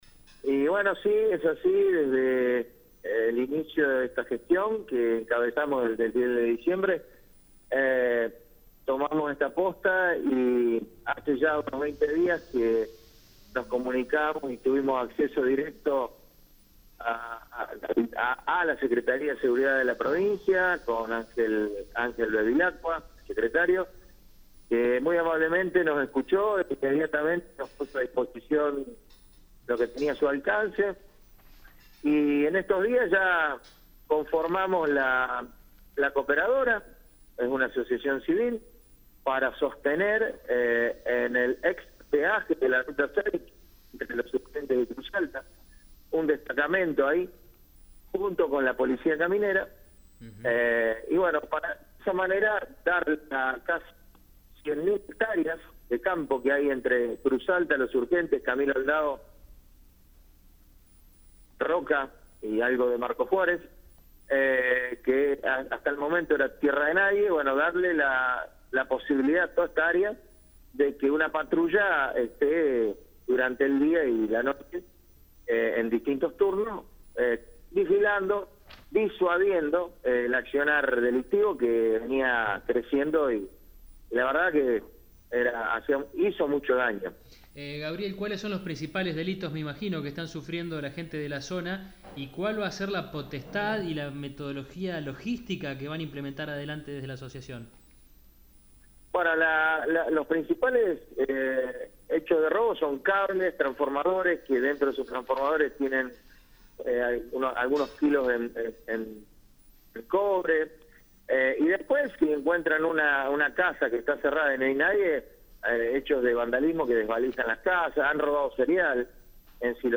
Según le manifestó el Intendente municipal de Los Surgentes, Ing.Agr. Gabriel Pellizón, a El Campo Hoy, se trata de una iniciativa tendiente a optimizar la seguridad en al zona a través de una metodología inédita.